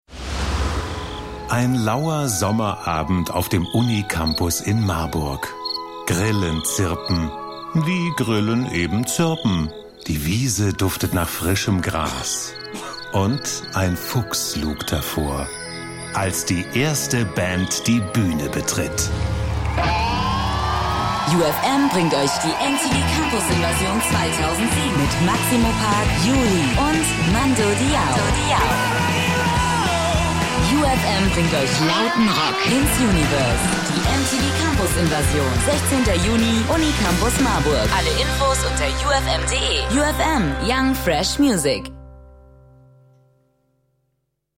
Sprecher deutsch.
Sprechprobe: Sonstiges (Muttersprache):
german voice over artist